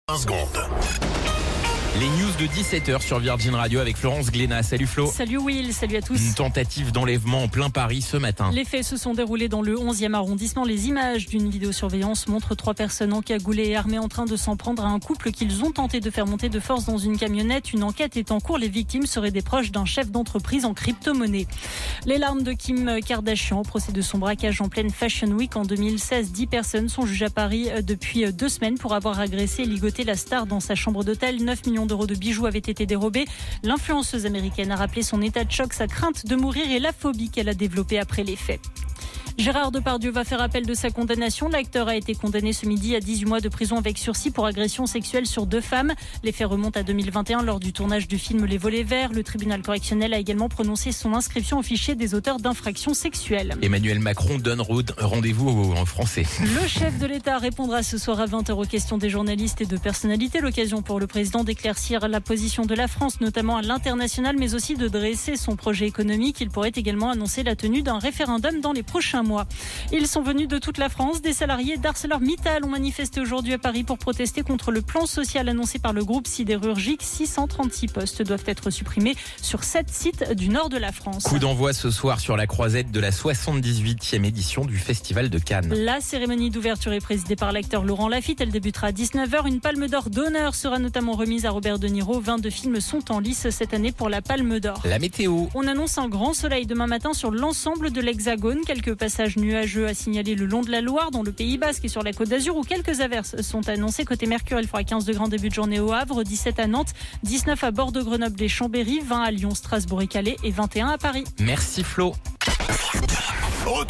Flash Info National 13 Mai 2025 Du 13/05/2025 à 17h10 .